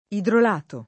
[ idrol # to ]